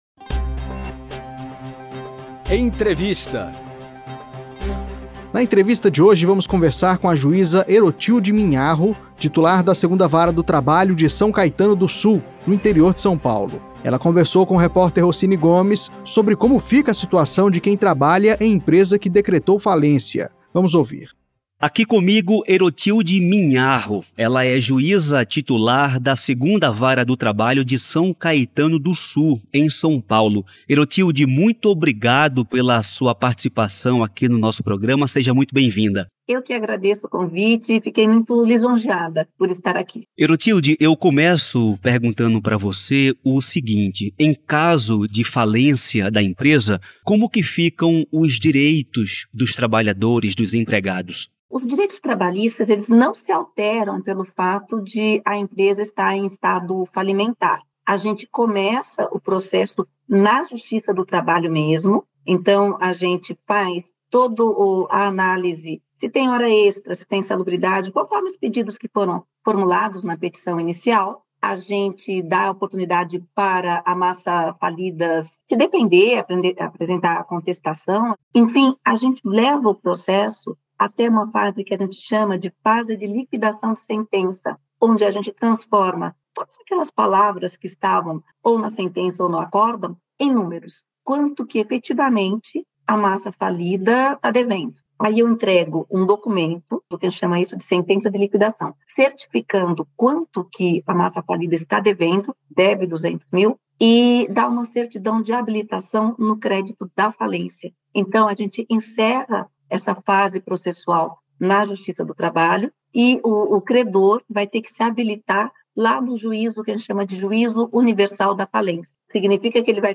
Em entrevista à Rádio TST, a juíza Erotilde Minharro explica o que acontece nessa circunstância